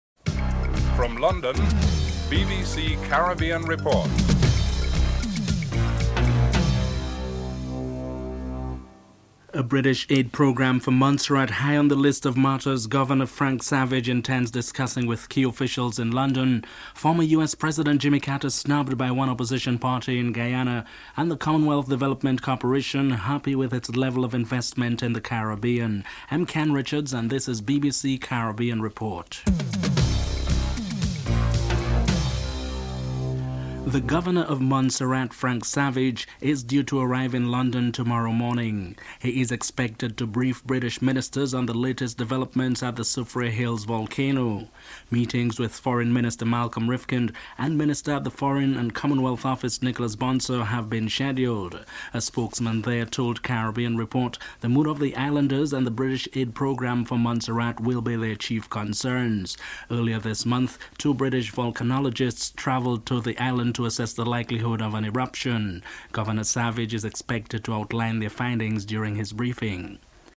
1. Headlines (00:00-00:33)
Political Leaders A.N.R. Robinson and Basdeo Panday are interviewed (03:00-05:03)